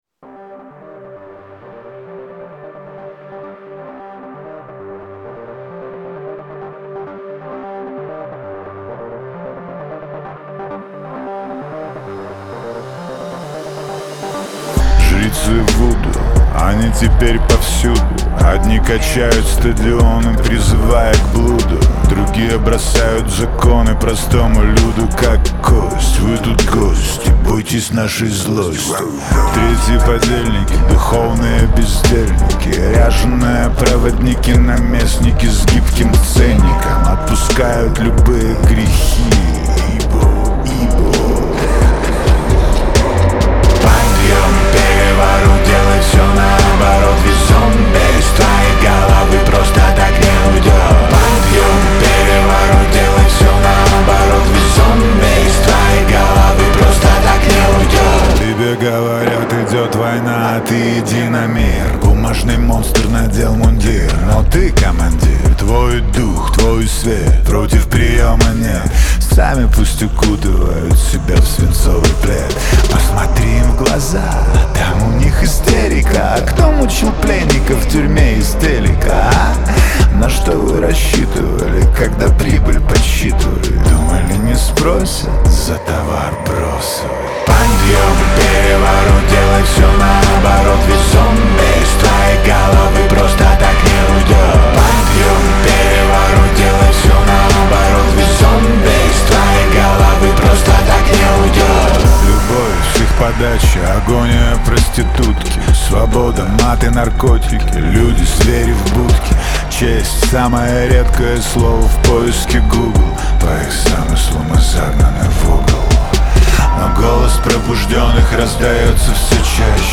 выполненная в жанре рок с элементами панка.